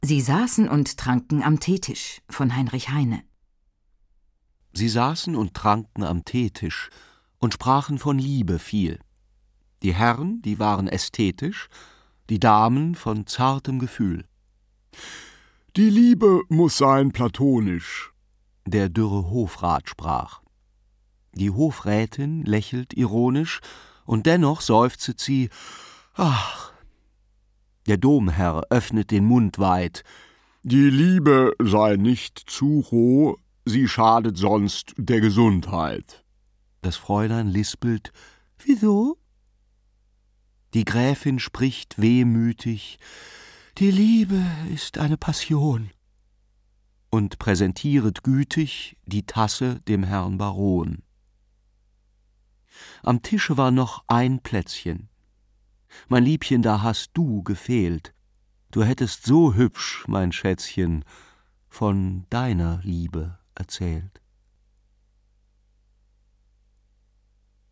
Großartige Sprecher interpretieren die schönsten Klassiker der Liebeslyrik umrahmt von wundervollen Klavierstücken (Brahms Walzer in As-Dur, Mendelssohn Bartholdy Frühlingslied, Beethoven Für Elise).